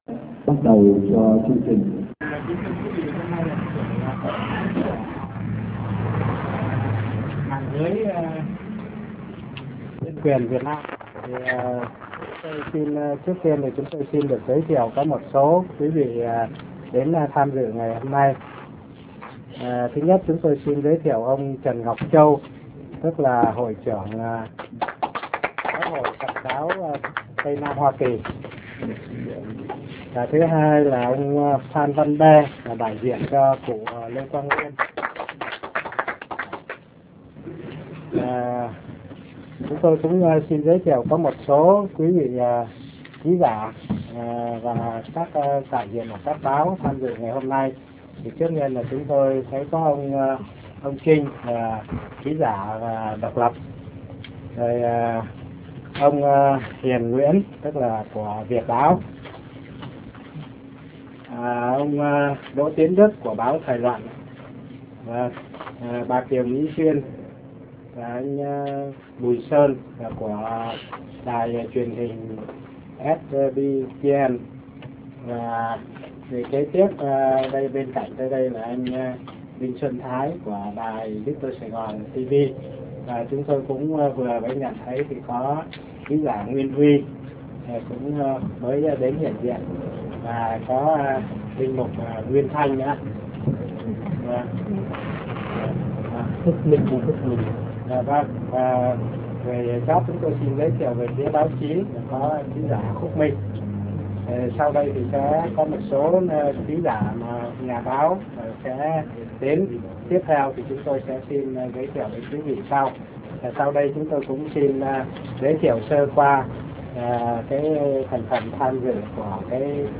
MẠNG LƯỚI NH�N QUYỀN HỌP B�O